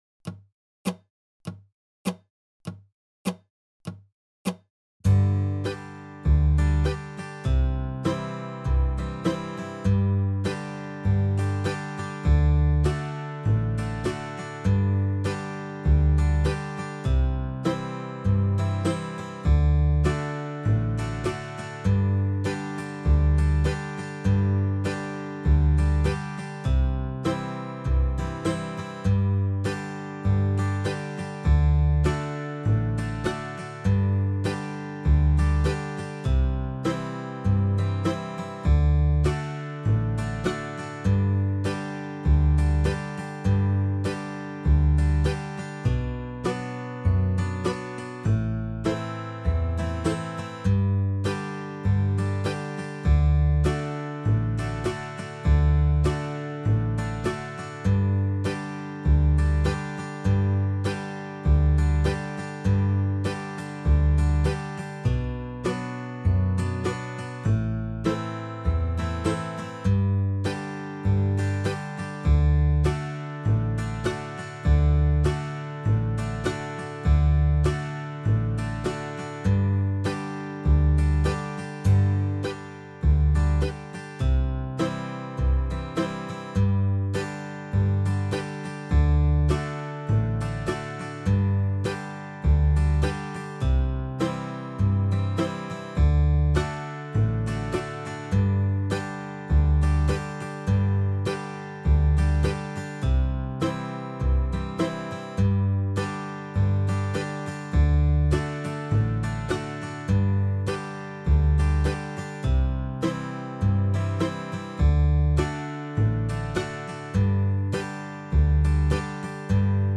October 2023 Dobro Zoom Workshops
Dixie Hoedown 50bpm.mp3